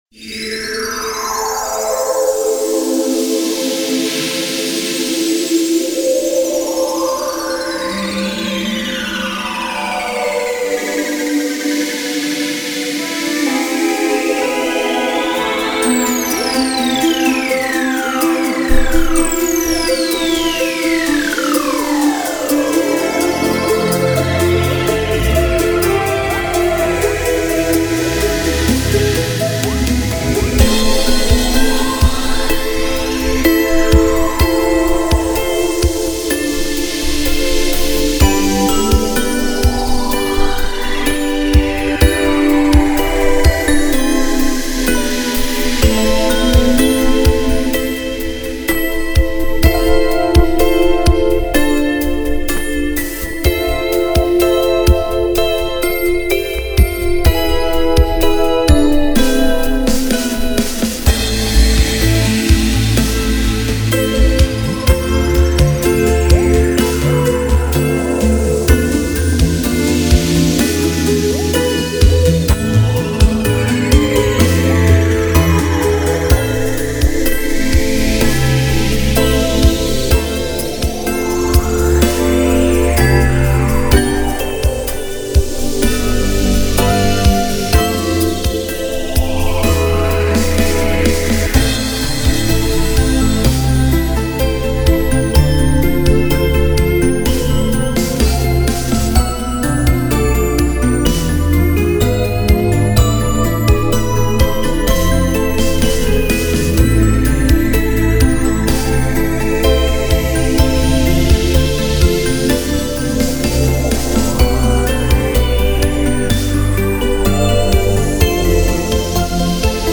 Подборка треков с улучшенным мною качеством звука